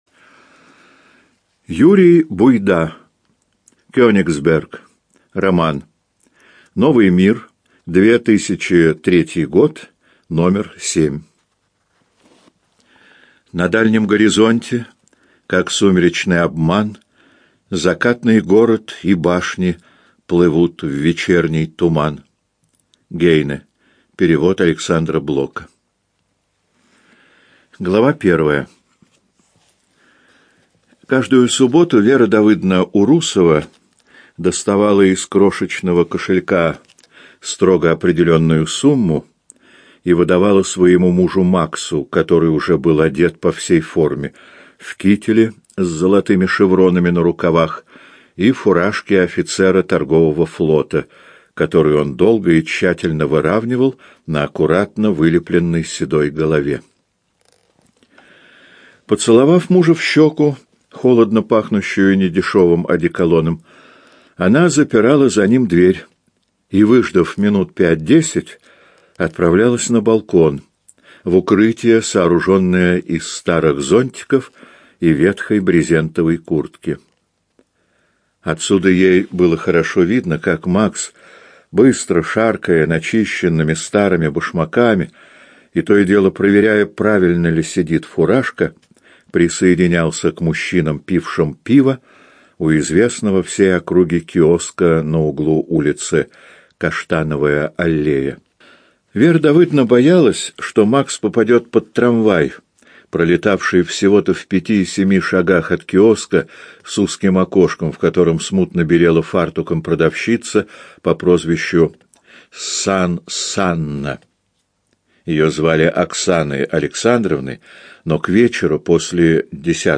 ЖанрСовременная проза
Студия звукозаписиЛогосвос